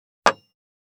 206,机に物を置く,テーブル等に物を置く,食器,グラス,コップ,工具,小物,雑貨,コトン,ト
コップ効果音物を置く